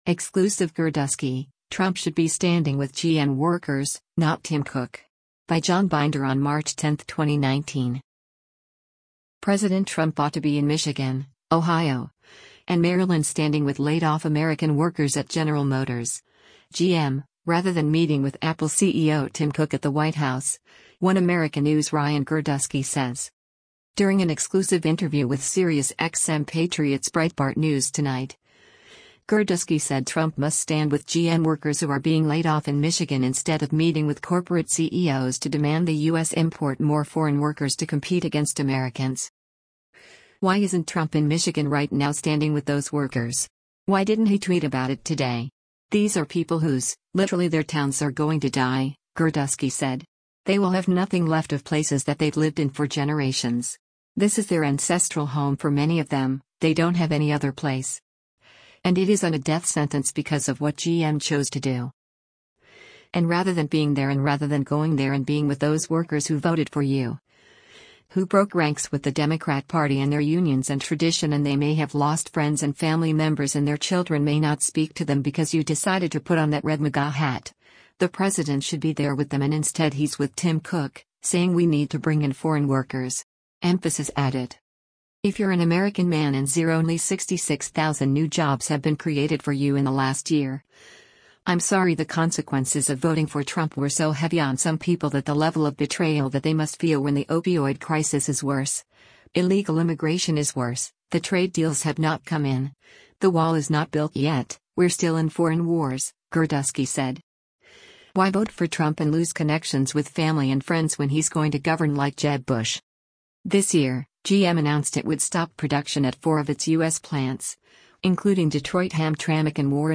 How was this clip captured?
Breitbart News Tonight broadcasts live on SiriusXM Patriot Channel 125 from 9:00 p.m. to Midnight Eastern (6-9:00 p.m. Pacific).